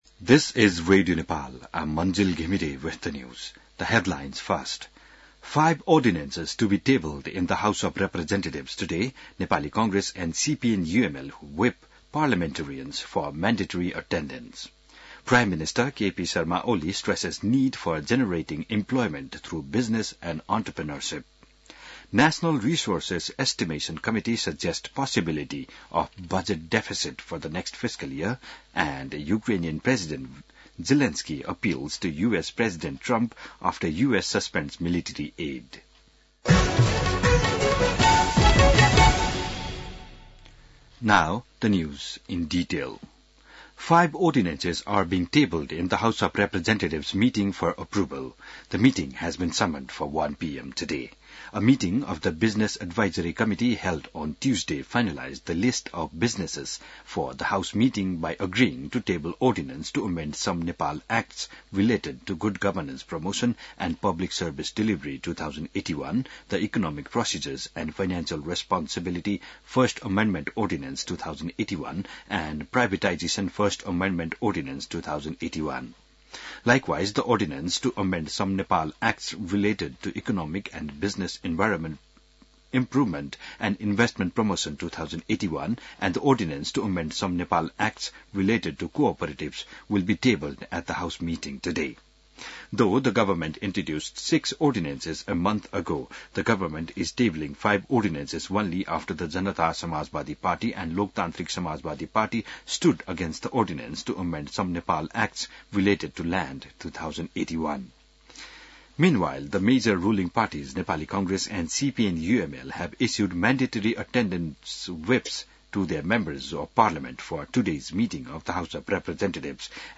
बिहान ८ बजेको अङ्ग्रेजी समाचार : २२ फागुन , २०८१